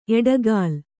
♪ eḍagāl